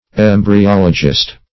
\Em`bry*ol"o*gist\